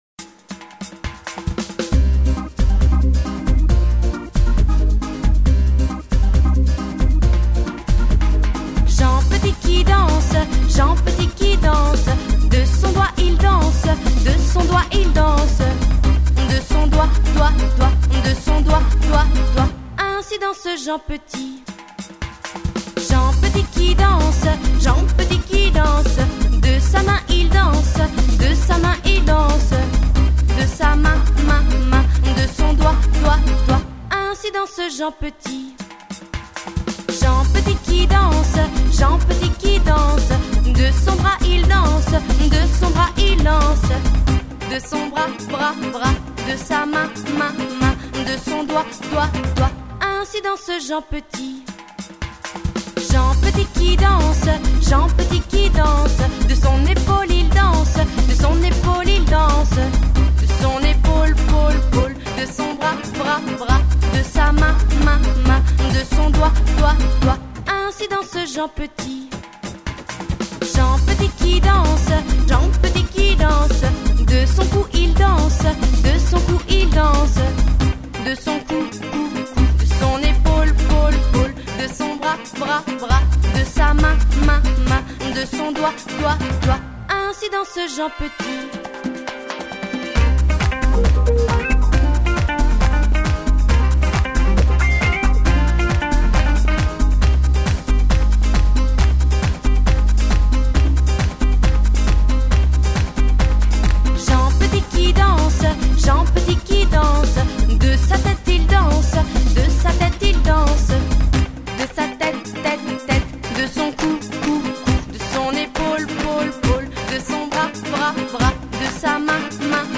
5 commentaires / Comptines et chansons pour les enfants
(version avec voix chantée)